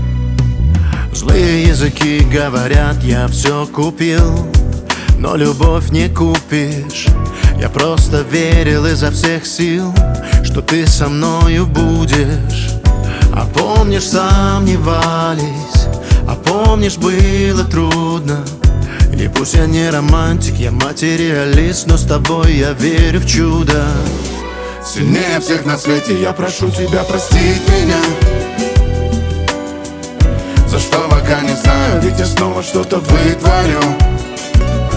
Pop Dance